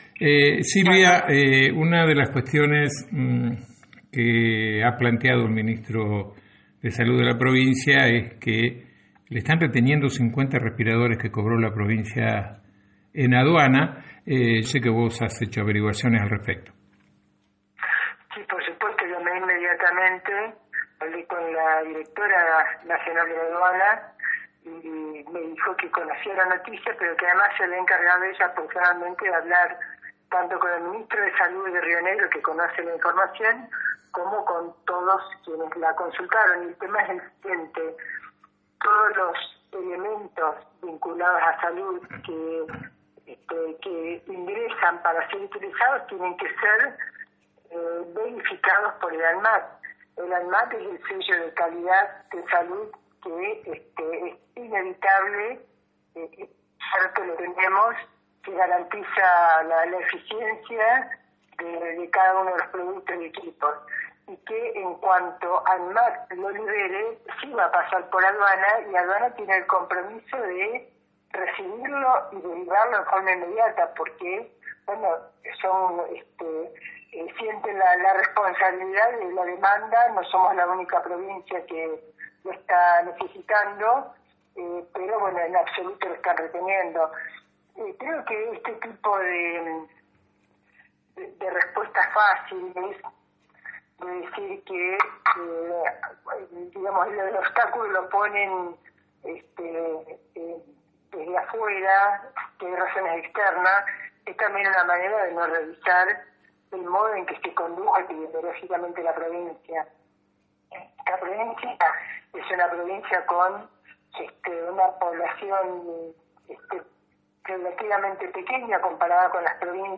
En comunicación telefónica con Antena Libre la ex diputada nacional por el Frente de Todos aseguró que el Gobierno miente, criticó el mal manejo de la pandemia en la provincia de Río Negro y manifestó que se están reteniendo 50 respiradores que no habrían pasado por la ADUANA.